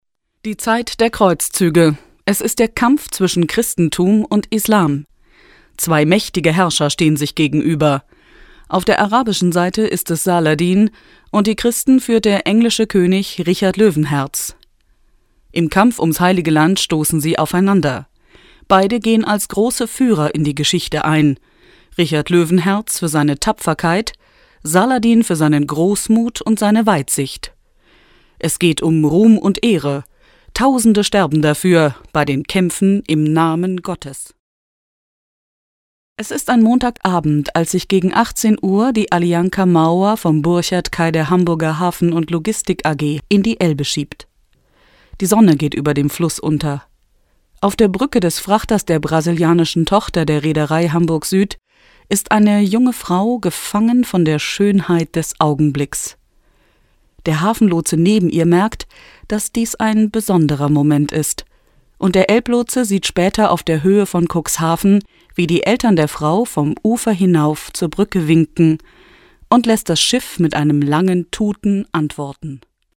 Sprechprobe: Sonstiges (Muttersprache):
Female german voices artist.